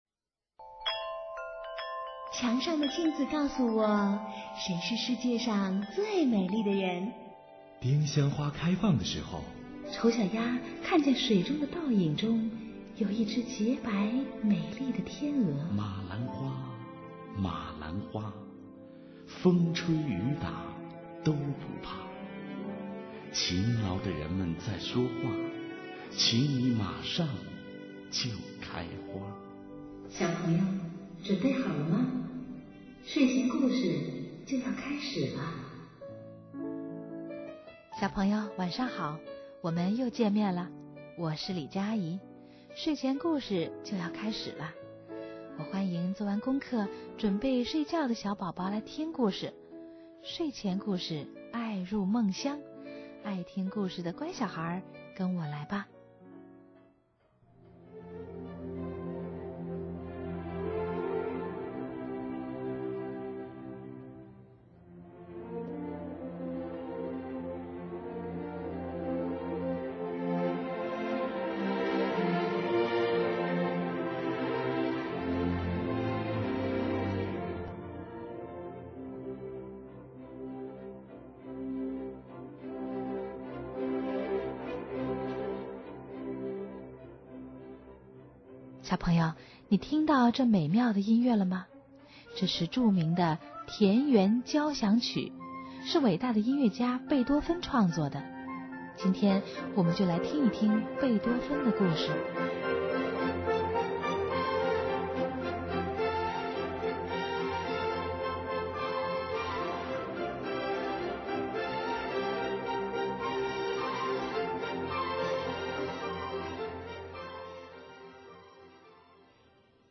睡前故事